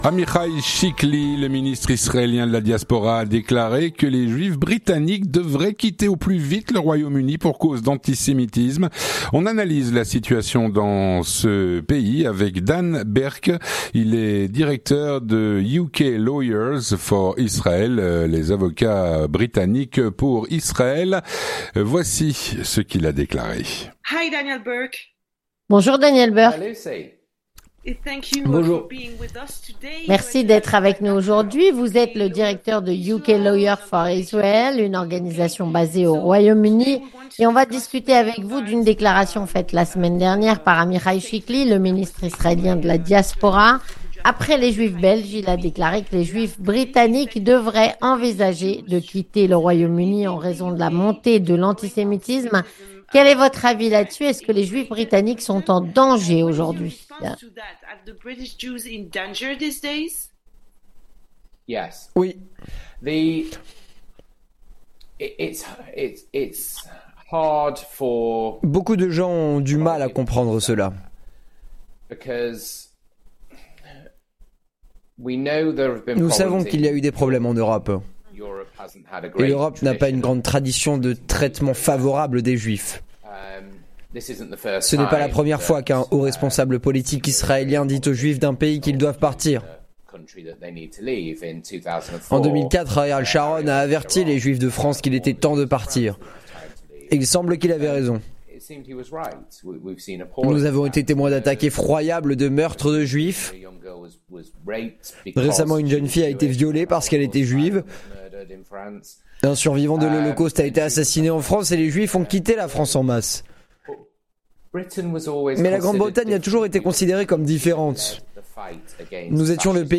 Rencontre - D'après Amichaï Chickli, les juifs britanniques devraient quitter au plus vite le Royaume-Uni.